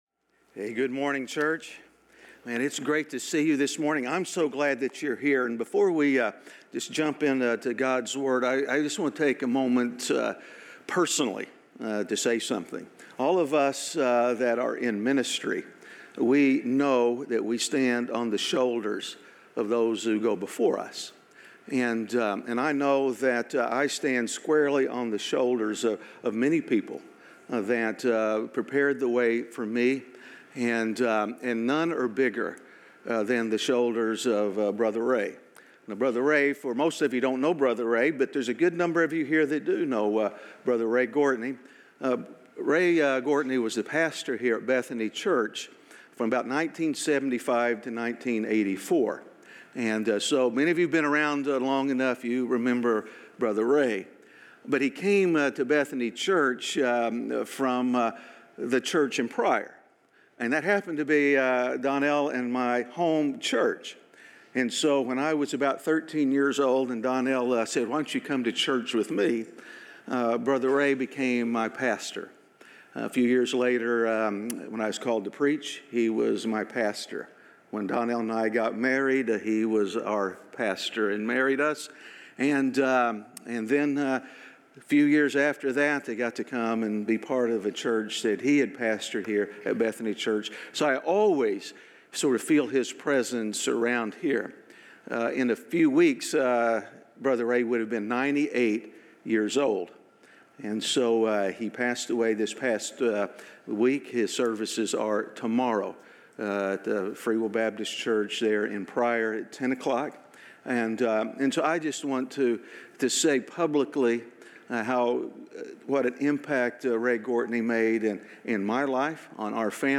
A message from the series "The God Who Speaks."